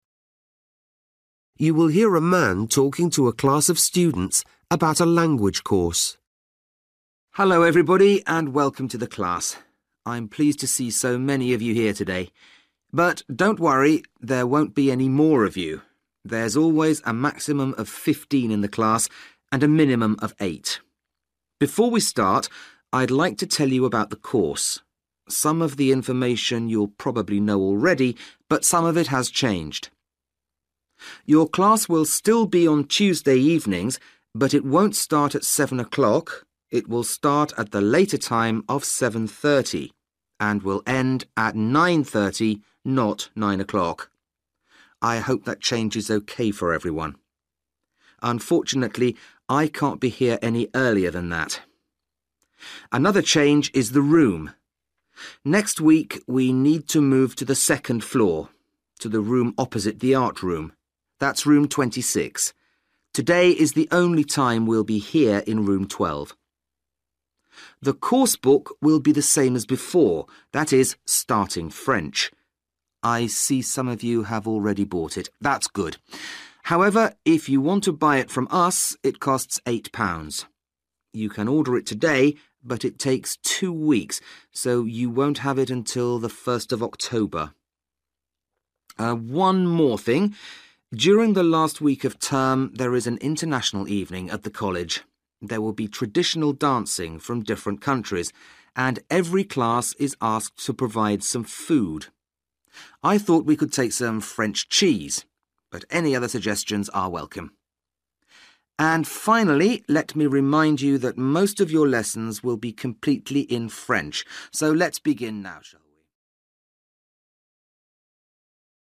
You will hear a man talking to a class of students about a language course.